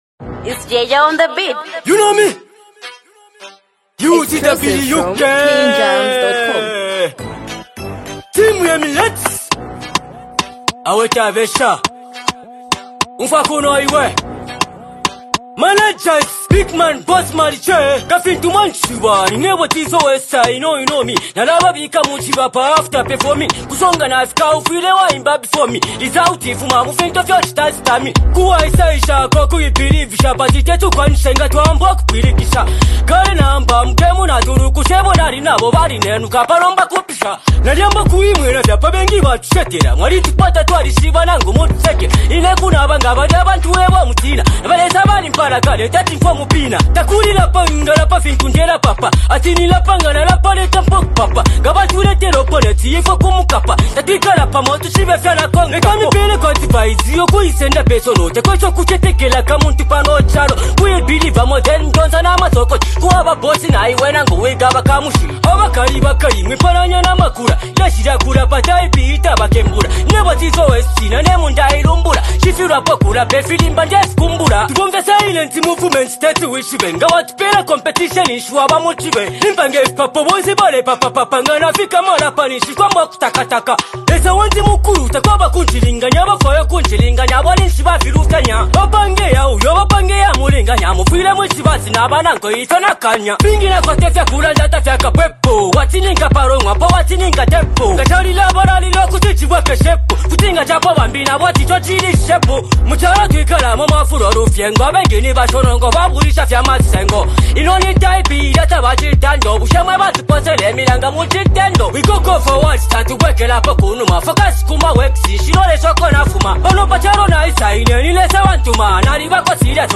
a confident and energetic rap track
uses sharp wordplay and expressive delivery
rap music